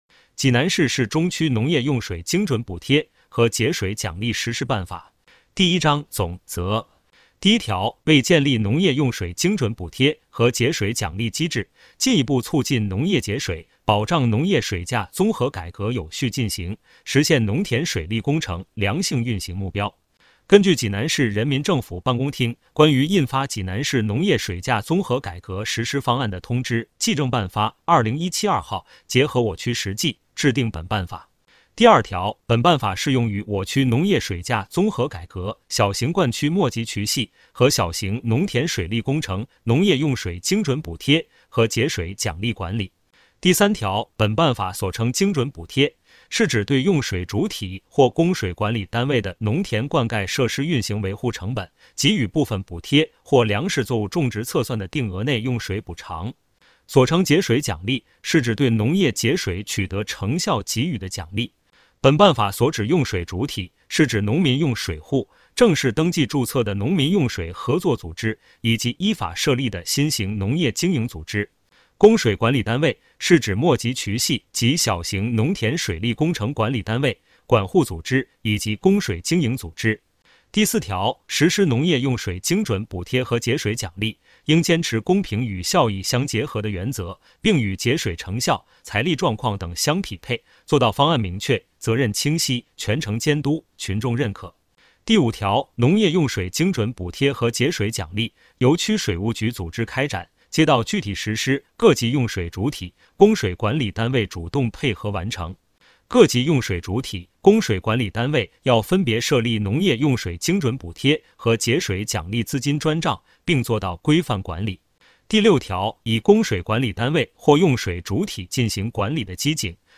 区水务局 - 有声朗读 - 音频解读：济南市市中区农业用水精准补贴和节水奖励实施办法